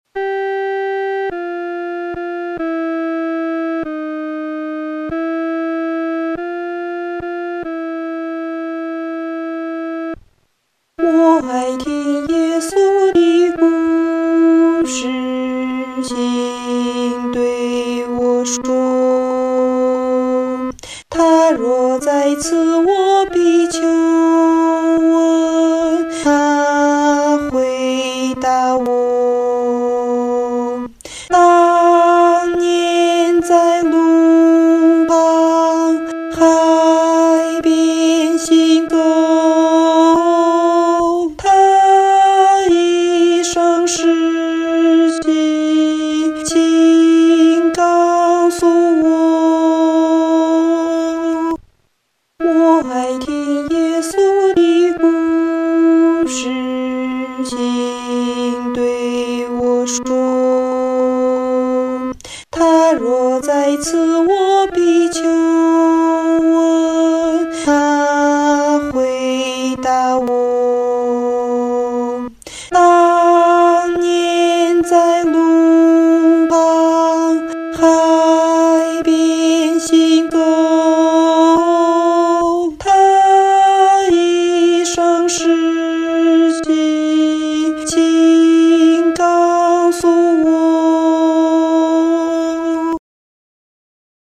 女高 下载